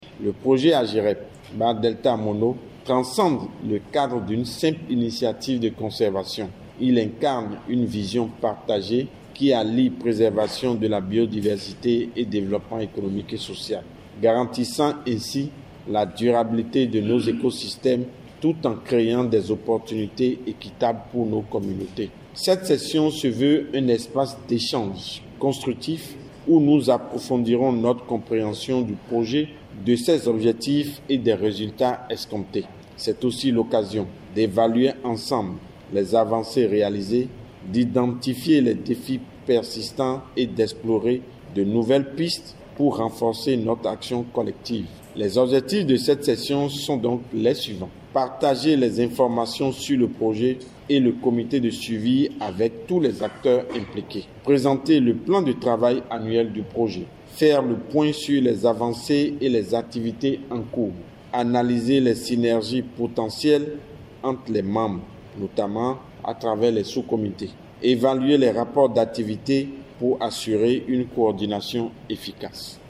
La session vise donc à partager avec les parties prenantes, les informations du comité de suivi du projet AGeReB Delta Mono et les activités mises en œuvre dans le cadre du projet AGeReB. Ghislain Constant GODJO secrétaire général du ministère du cadre de vie et des transports, président du comité de suivi à l’ouverture de l’atelier